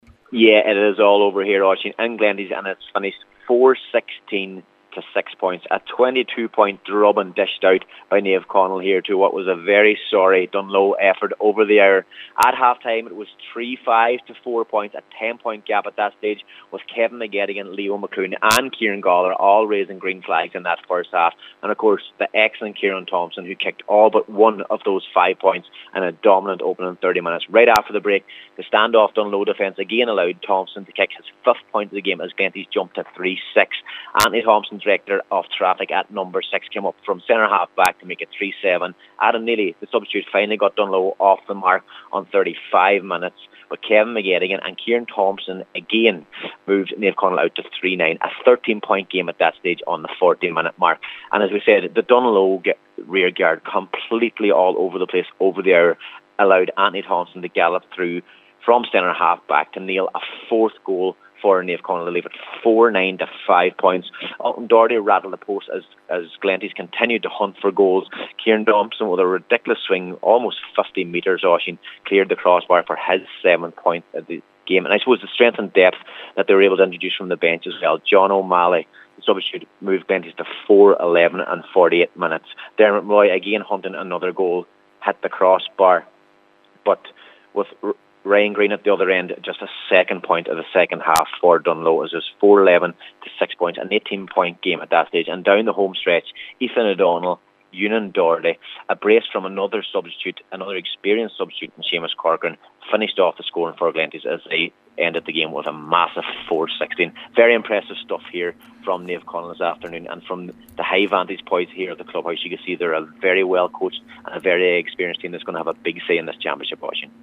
reports for Highland Radio Sport…